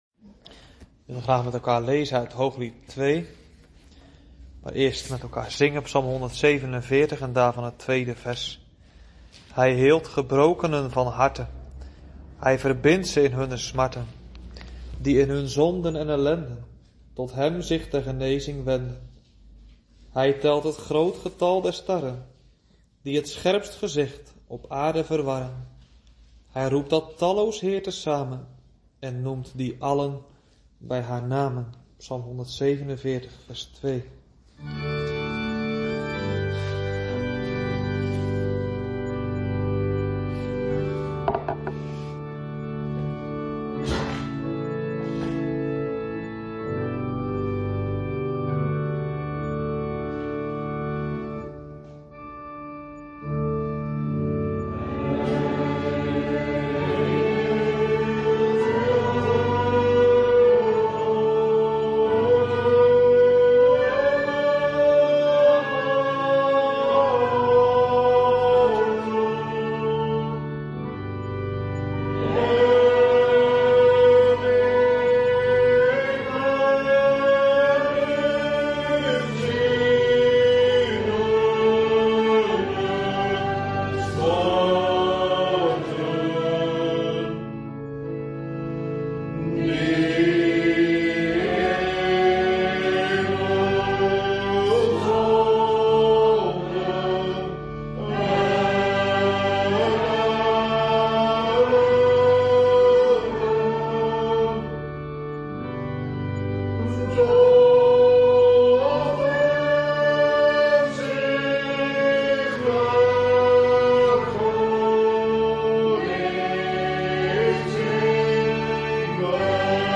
LEZING 37 - DORDTSE LEERREGELS - H5 - HET GEBED ALS MIDDEL TOT VOLHARDING - DEEL 4 - BIDDEN EN SMEKEN OM DATGENE WAT WIJ NODIG HEBBEN